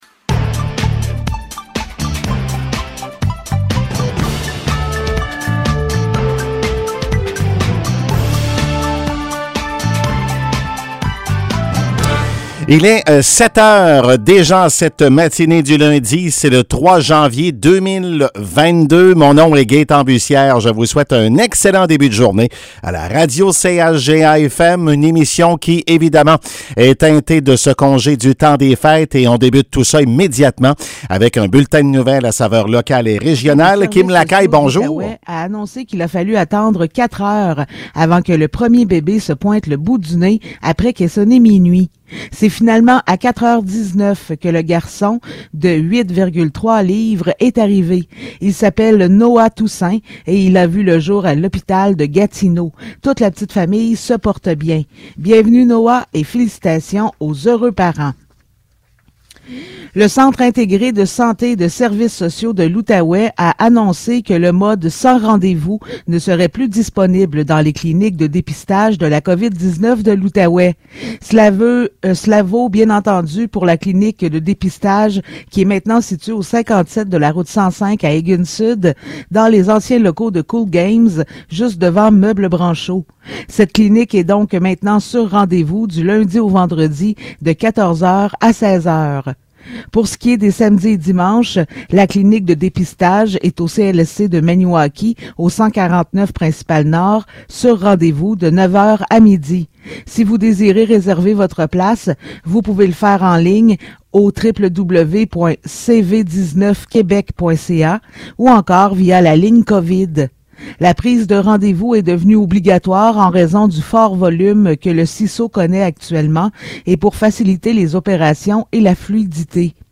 Nouvelles locales - 3 janvier 2022 - 7 h